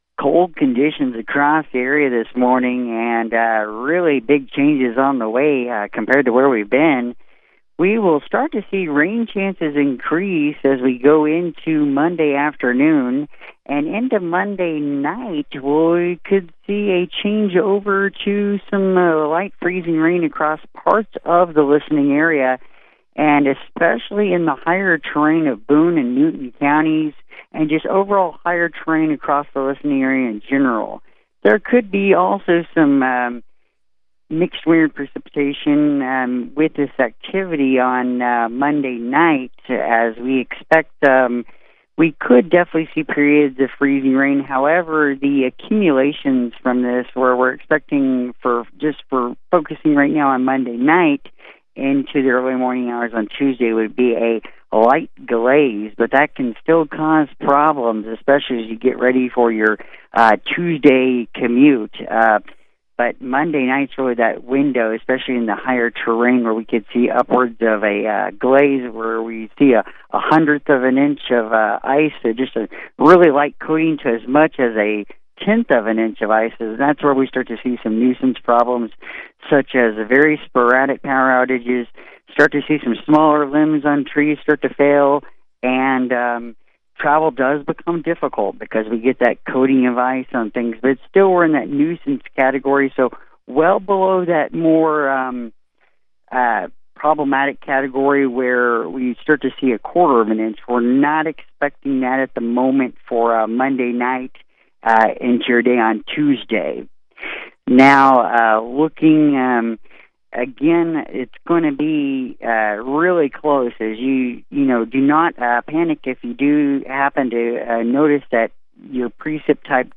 Temperatures have trended slightly warmer in the forecast for Monday night into Tuesday morning lowering the chances of widespread freezing rain. The greatest chance of freezing rain and accumulation remains in the higher elevations of Boone County. National Weather Service Meteorologist